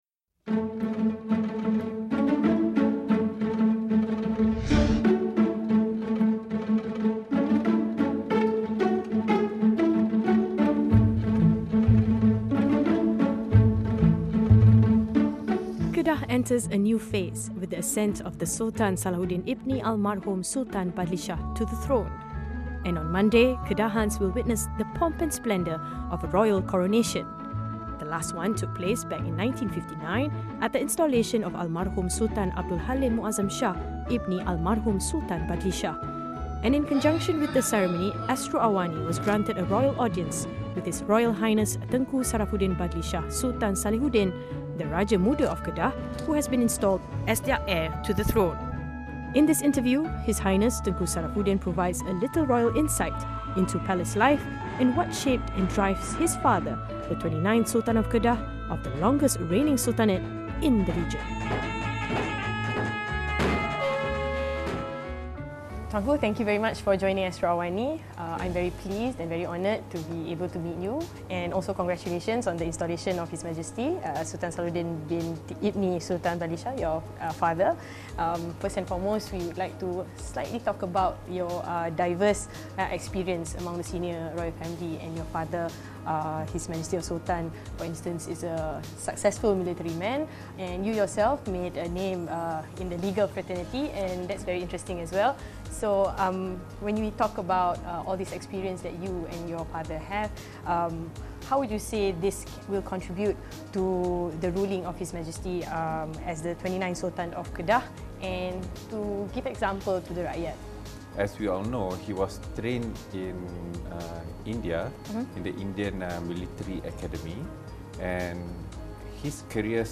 Special Interview with Raja Muda Kedah